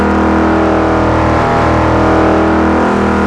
mere63_mid.wav